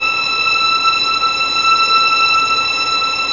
Index of /90_sSampleCDs/Roland L-CD702/VOL-1/STR_Vlns Bow FX/STR_Vls Sul Pont